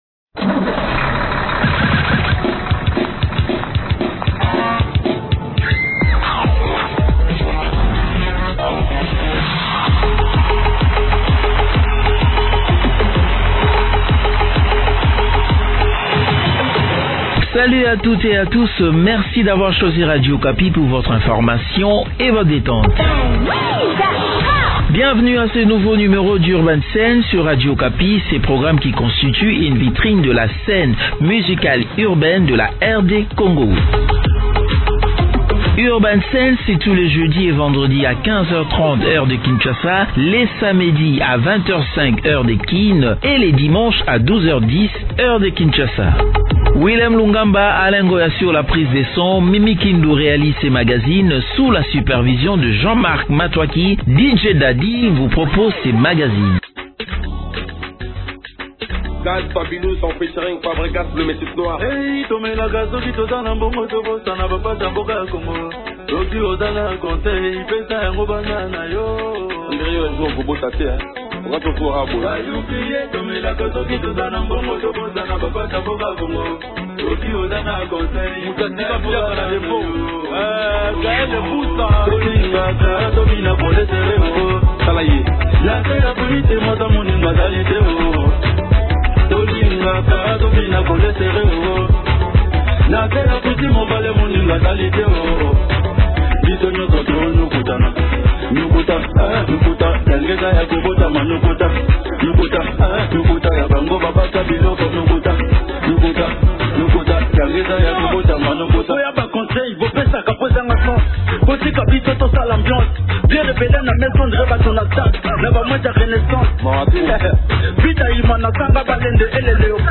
Votre magazine de musique urbaine, Urban Scène